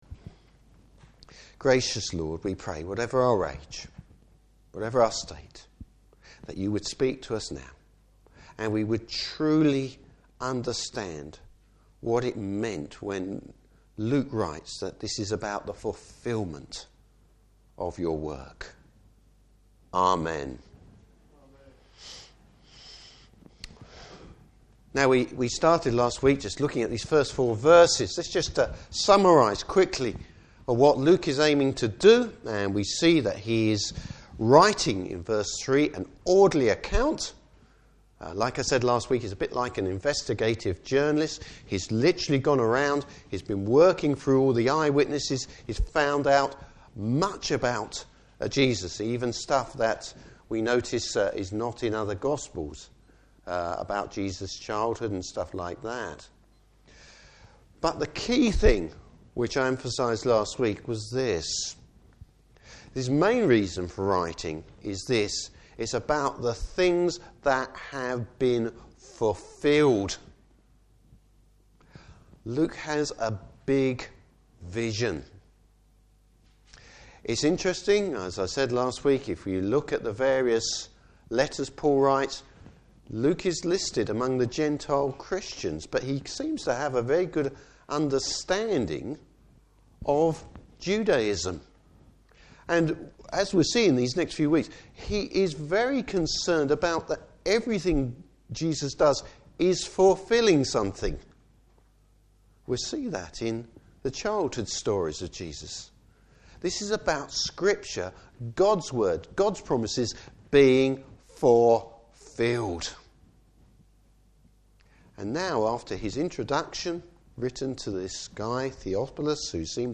Service Type: Morning Service Bible Text: Luke 1:5-25.